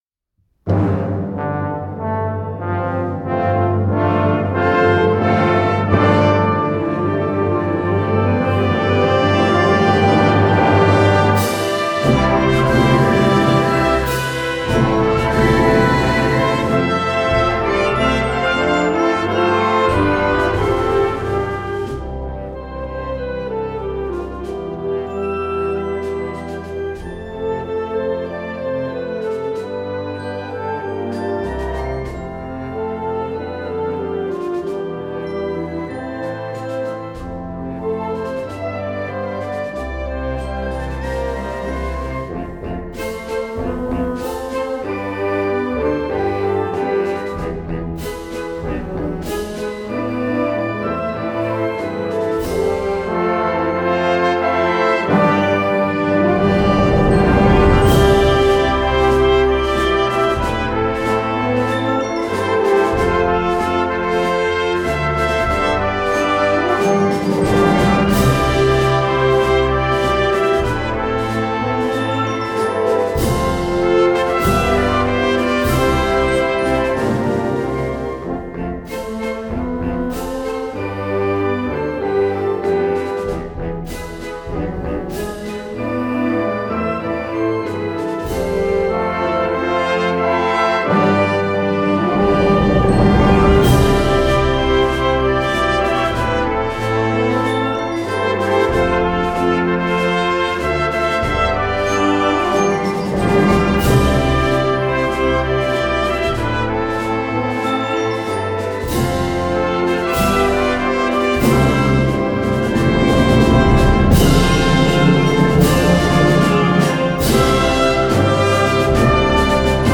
吹奏楽